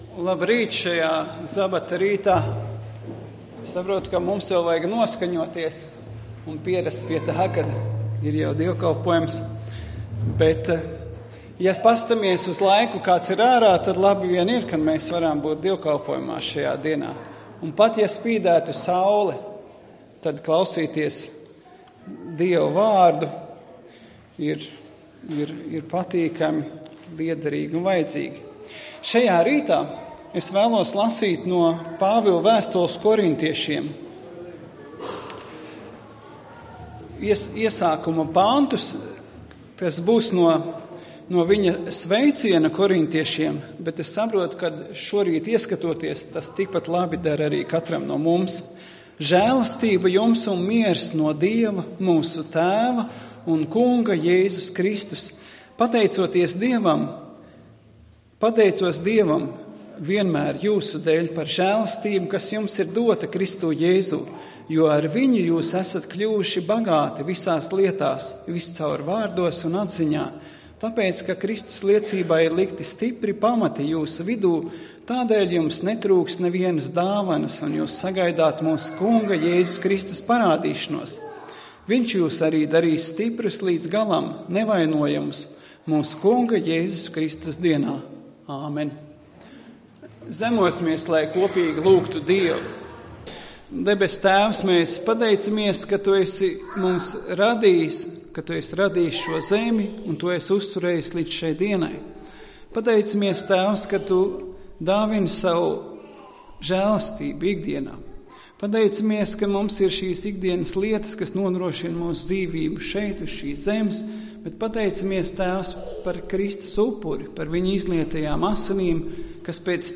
Svētrunas